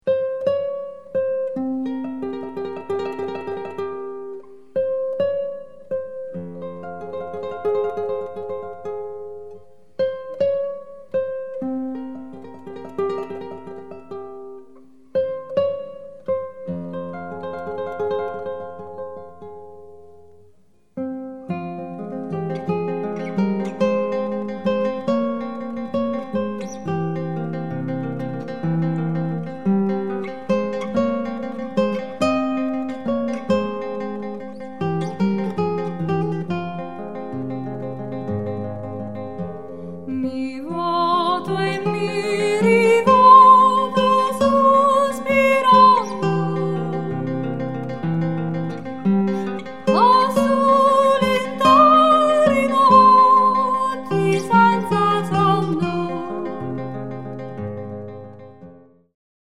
Volkslieder aus Süditalien
Gesang, Perkussion
Gitarre